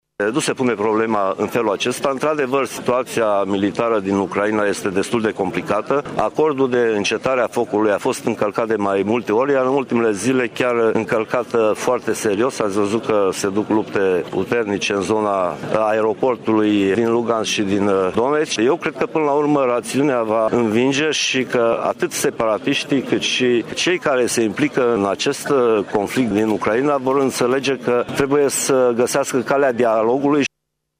Declaraţia aparţine Ministrului Apărării Naţionale, Mircea Duşa, prezent astăzi la Tîrgu-Mureş pentru festivităţile militare de Ziua Unirii.
Ministrul Apărării, Mircea Duşa: